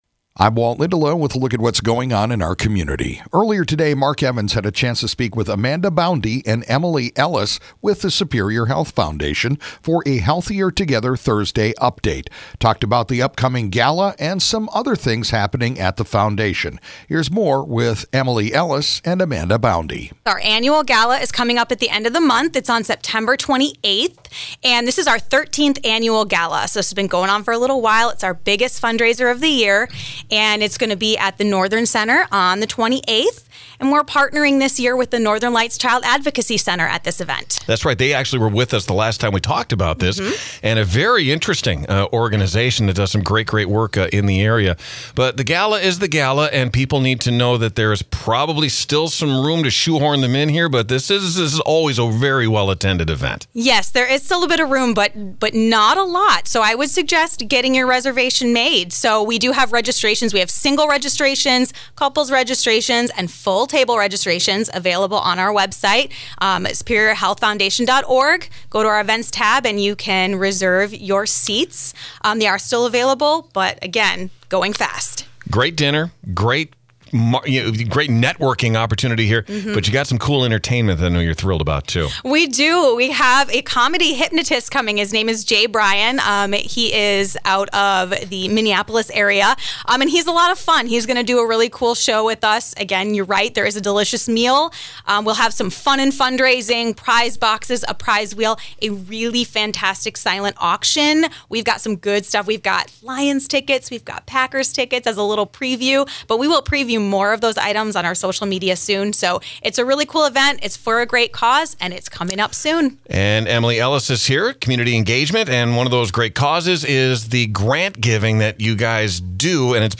shared an update